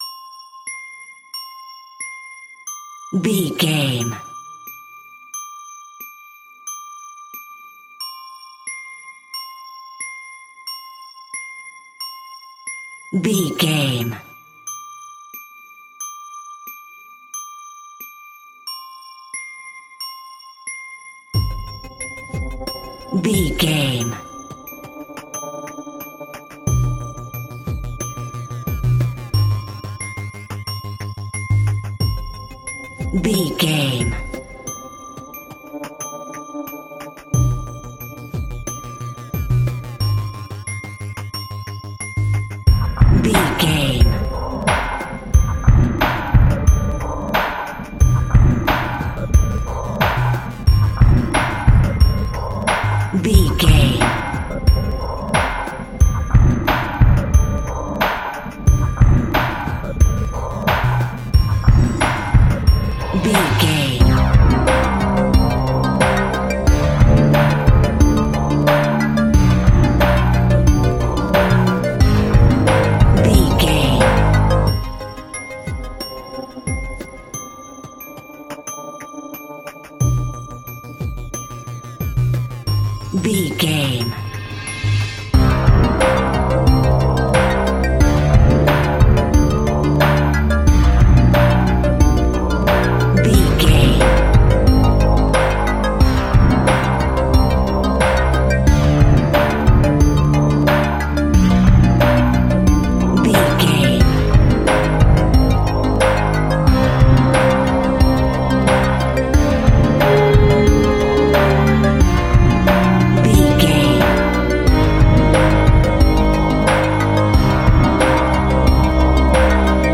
In-crescendo
Thriller
Aeolian/Minor
ominous
eerie
synthesizer
horror music
Horror Pads
horror piano
Horror Synths